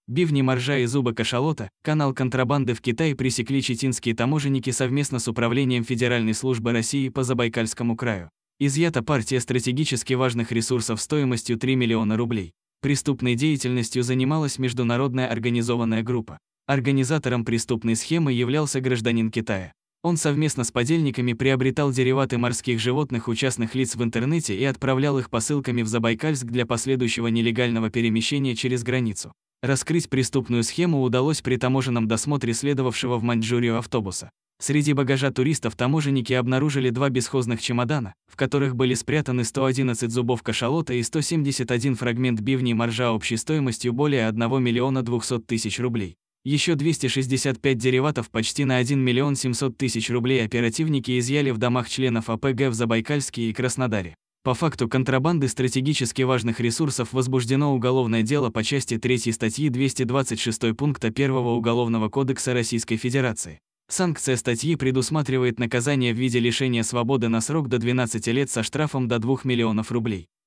Аудио вариант новости !